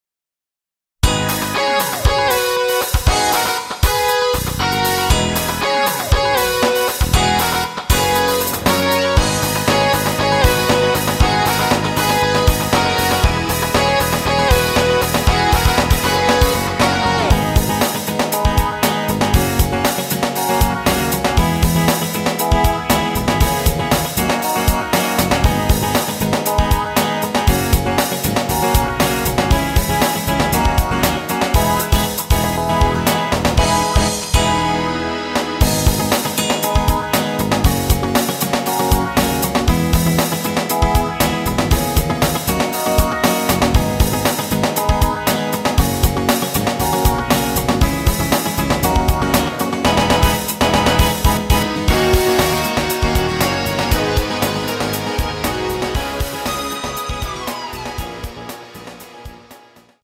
Abm
앨범 | O.S.T
앞부분30초, 뒷부분30초씩 편집해서 올려 드리고 있습니다.